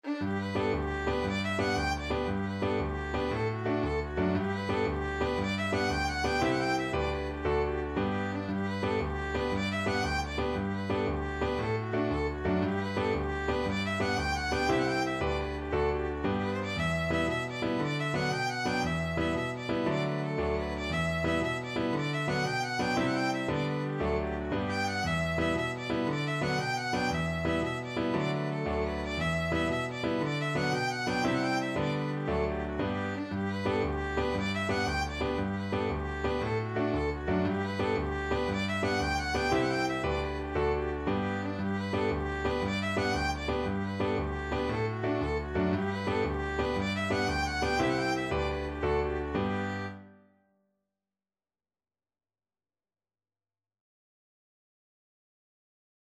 Violin
G major (Sounding Pitch) (View more G major Music for Violin )
6/8 (View more 6/8 Music)
With energy .=c.116
Irish